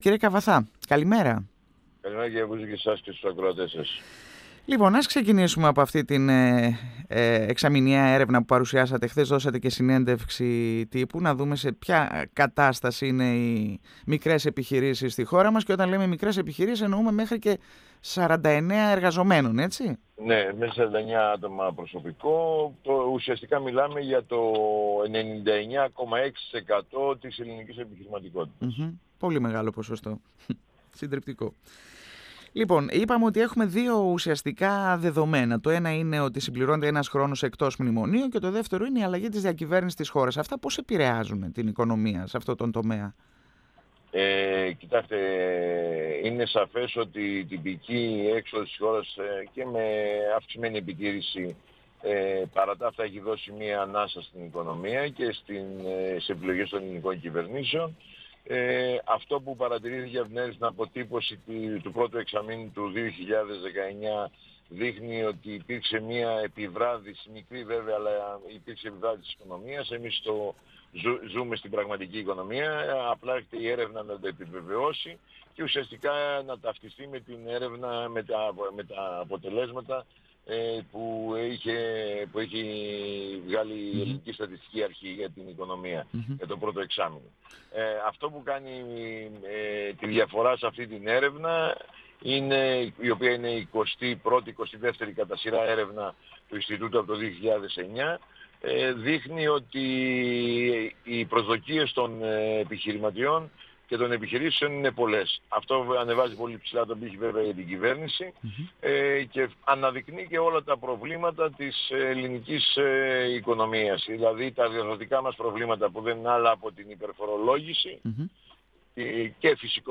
μίλησε στον 102 fm της ΕΡΤ3